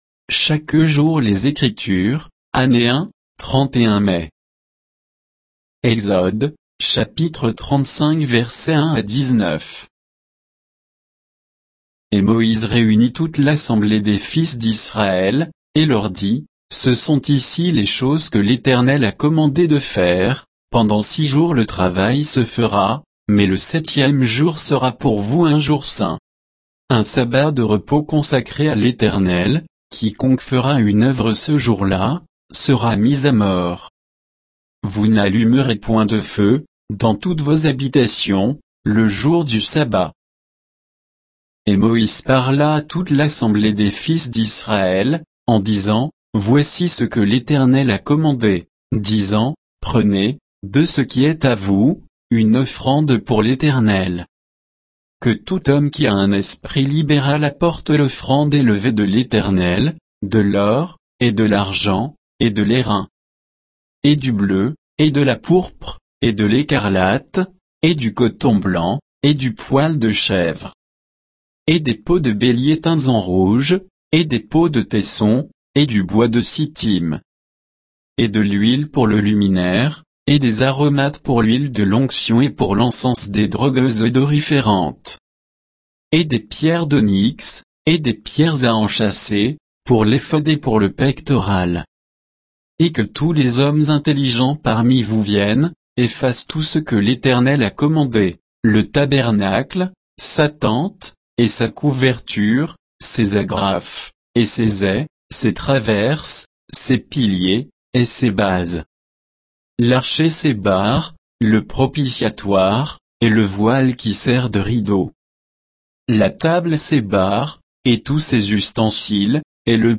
Méditation quoditienne de Chaque jour les Écritures sur Exode 35